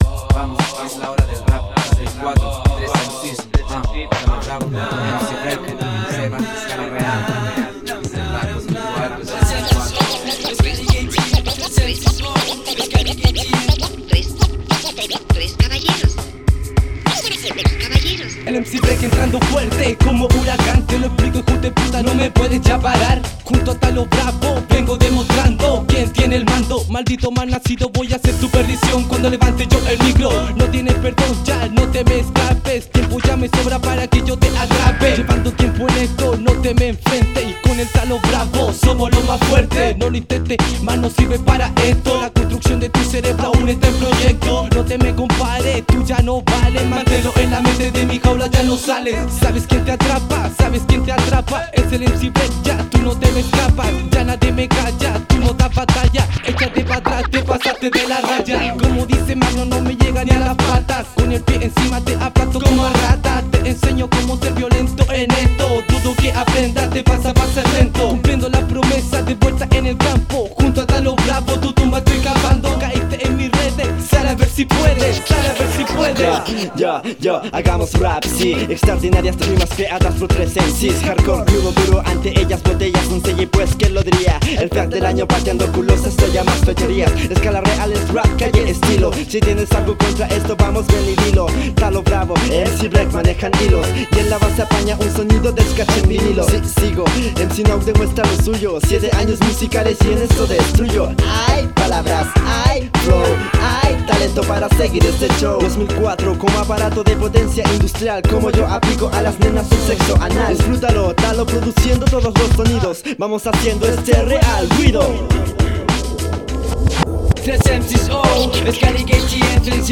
Rap echo desde puente alto / la florida: